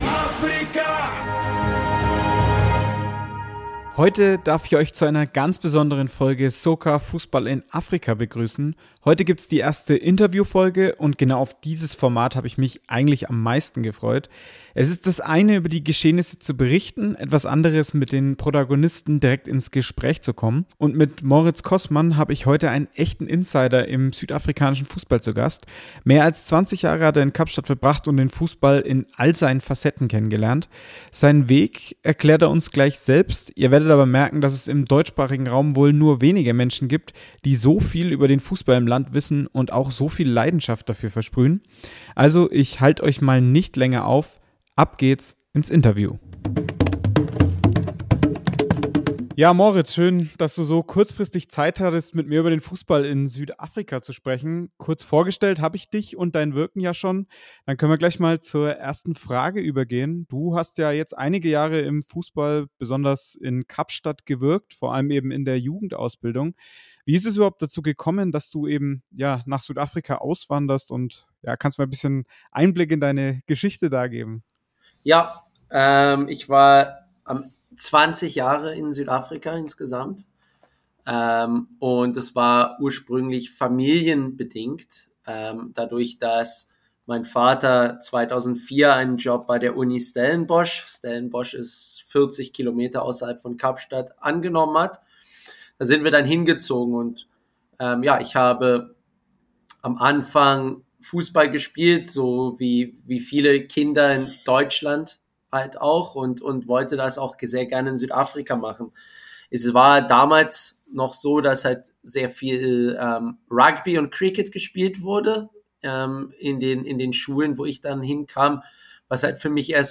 Interviewfolge